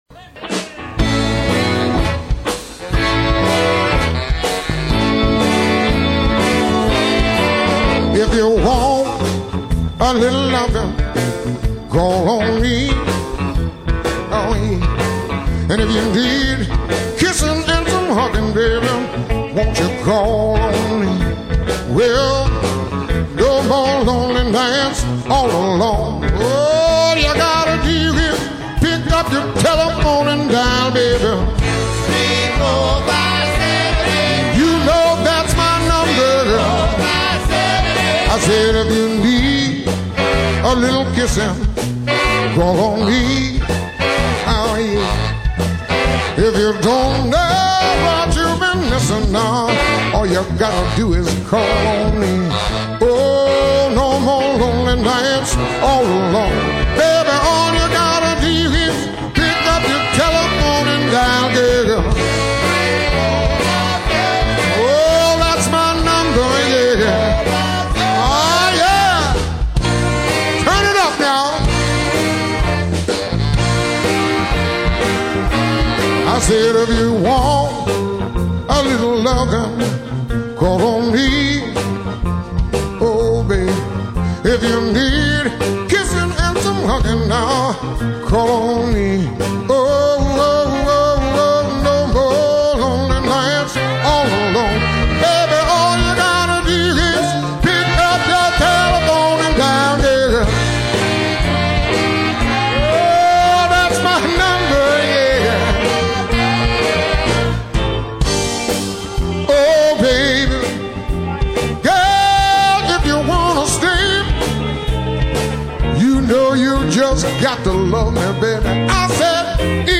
R&B, Soul, and Funk<
Roger Sherman Baldwin Park Greenwich, CT
( 2 trk live recording)